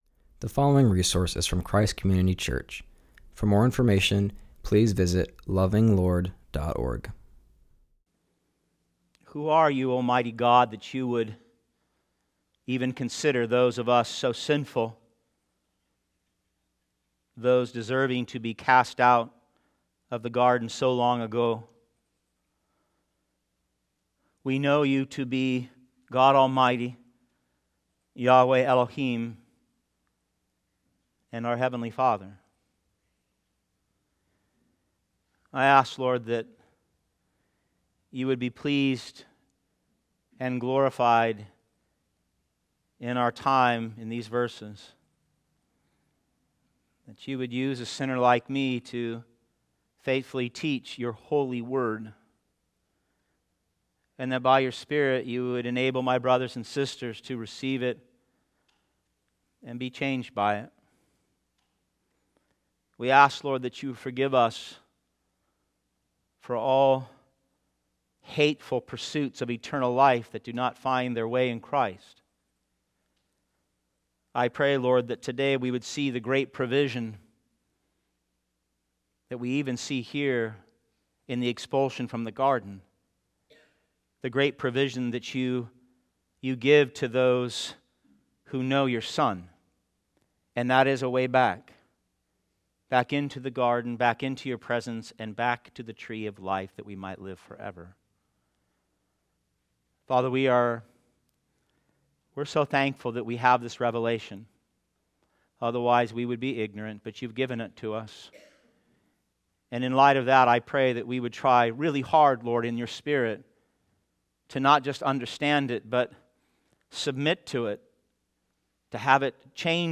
preaches from Genesis 3:22-24